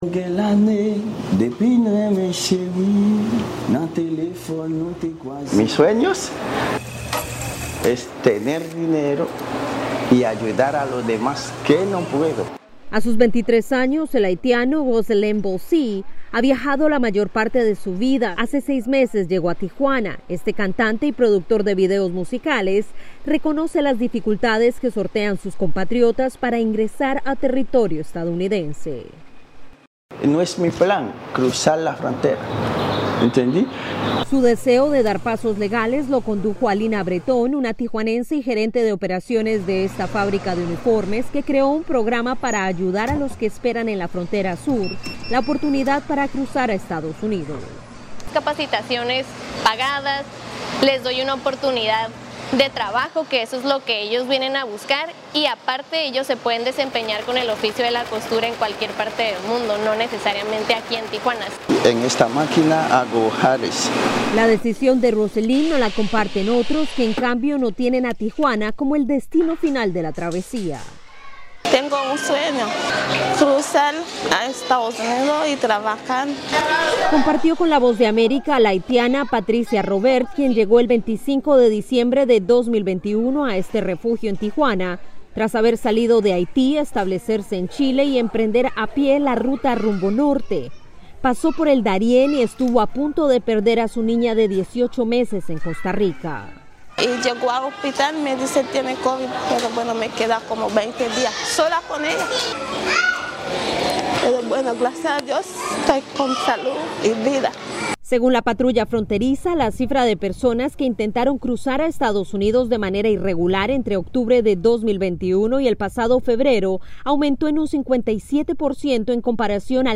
Nuestra enviada especial a la frontera